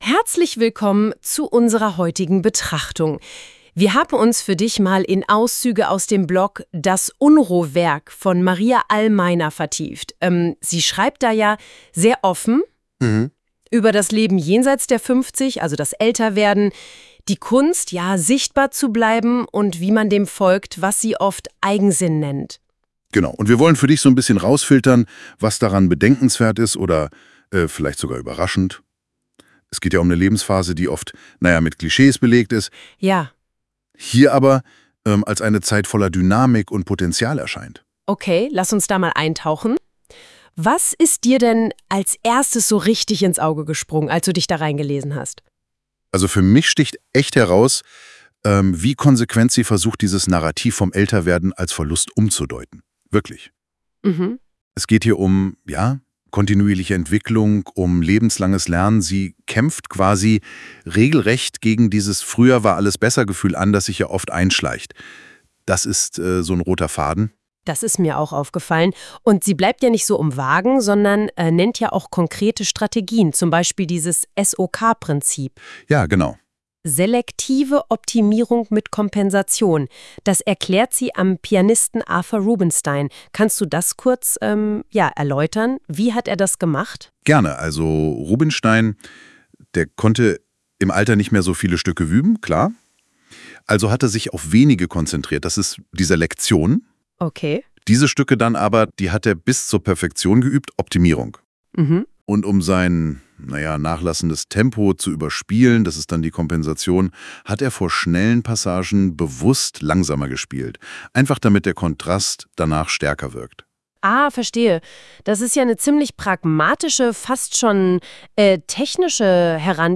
Das Prinzip ist recht simpel: Eine männliche und eine weibliche Stimme unterhalten sich. Leider sogar täuschend menschenähnlich.
Da unterbricht der Sprecher die Sprecherin ganz am Anfang mit einem Hmm.